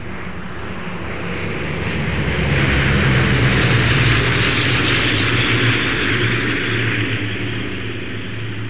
دانلود صدای طیاره 26 از ساعد نیوز با لینک مستقیم و کیفیت بالا
جلوه های صوتی
برچسب: دانلود آهنگ های افکت صوتی حمل و نقل دانلود آلبوم صدای طیاره از افکت صوتی حمل و نقل